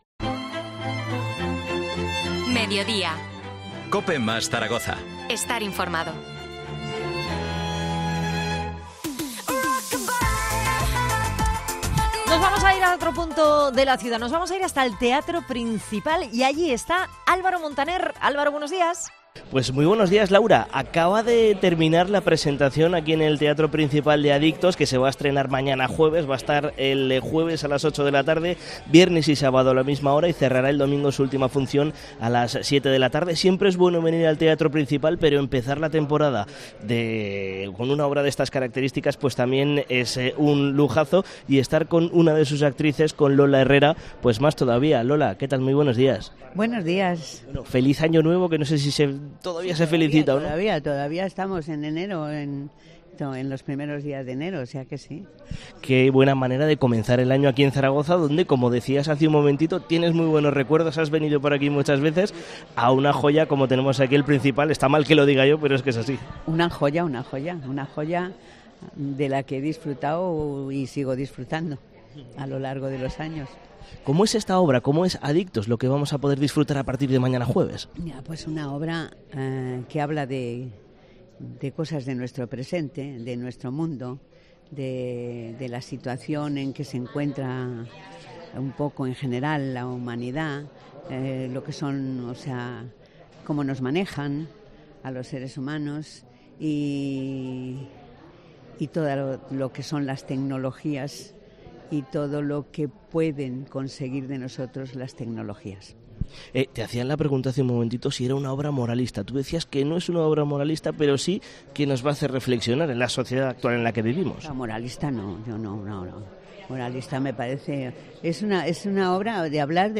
Entrevista a Lola Herrera